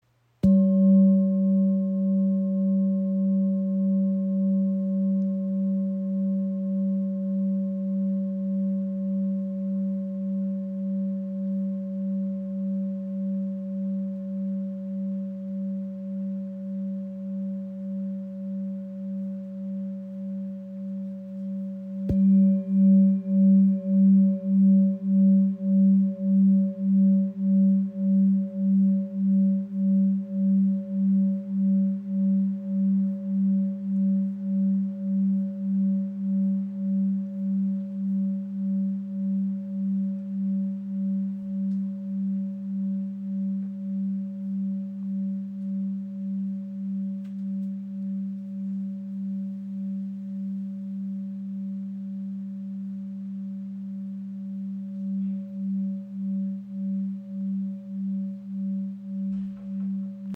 Klangschale Lebensblume | ø 20.8 cm | Ton ~ G | Erdrotation / Tageston (194,18 Hz)
Handgefertigte Klangschale aus Kathmandu
• Icon Inklusive passendem rotem Filzschlägel
Die Erd-Klangschale mit 194,18 Hz trägt die sanfte Schwingung des Tagestons der Erde in sich.
Seine sanfte Schwingung erdet, entspannt und stärkt das Gefühl von Stabilität und innerer Ruhe.